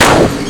OS Slaves hit 1.wav